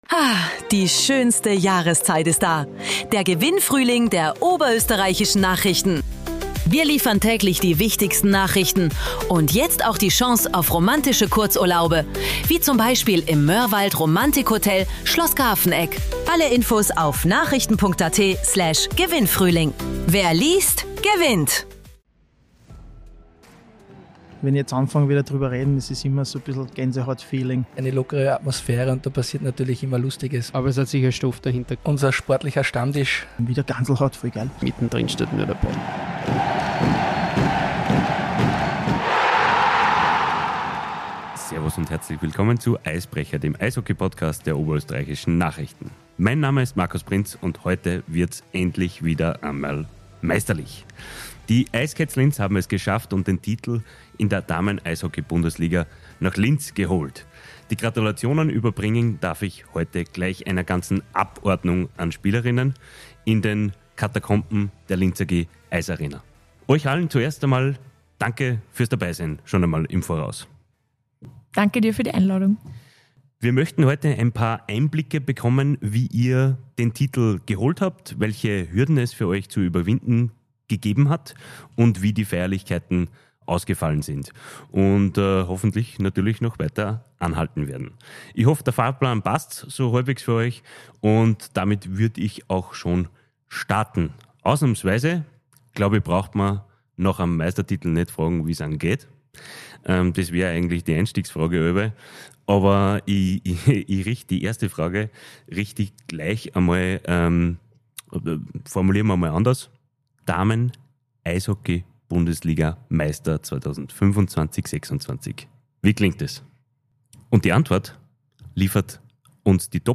Eine Abordnung des Meisterteams spricht in Folge 113 über den Weg zum Titel in der Damen-Eishockey-Bundesliga, welche Hürden die Linzerinnen überwinden mussten und wie die Feierlichkeiten ausgesehen haben.